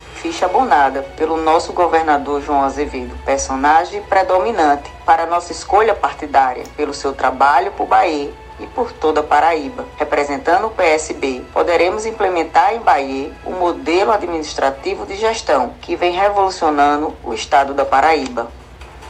Em entrevista ao programa Arapuan Verdade, da Rádio Arapuan FM